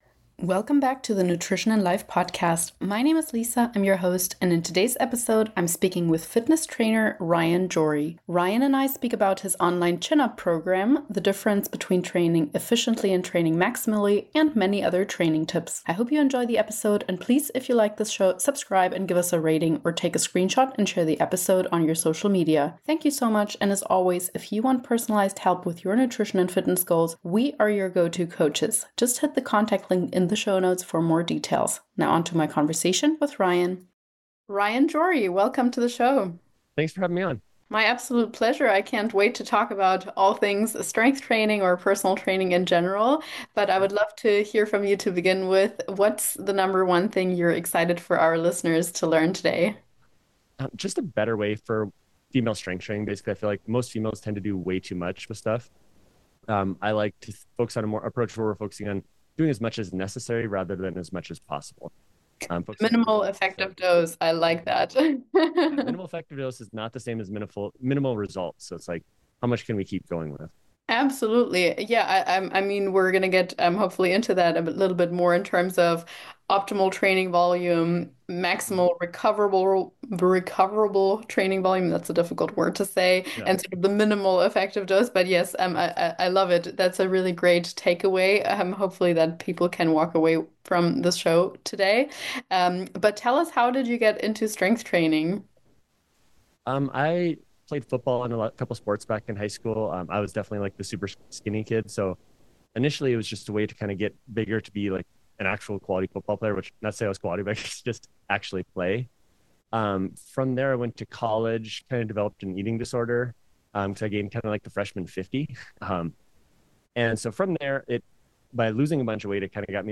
What we talk about in this convo can help you wherever you are in your wellness journey! Just 2 coaches sitting down talking from our lived experiences in order to encourage you.